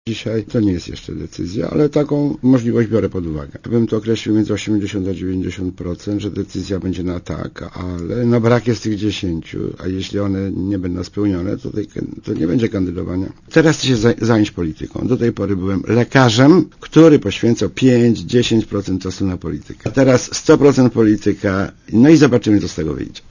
Komentarz audio W ostatnich sondażach zaufania profesor Religa zdobył aż 65% poparcia i zajął pierwsze miejsce.